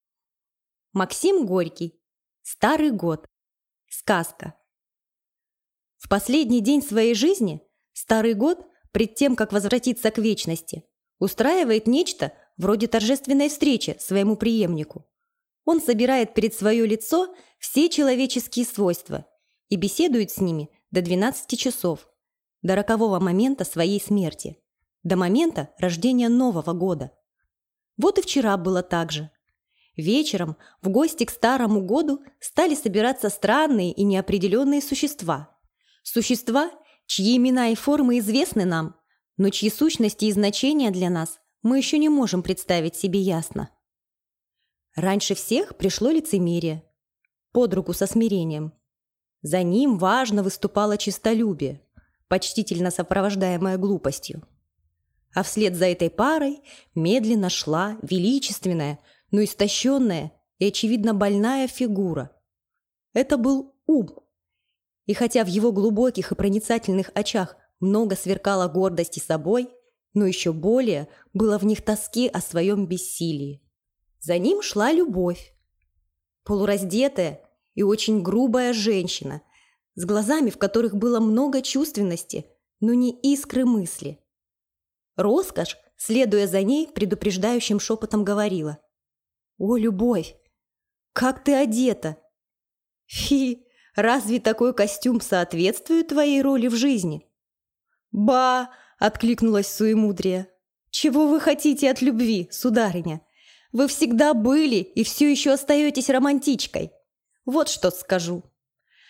Аудиокнига Старый год | Библиотека аудиокниг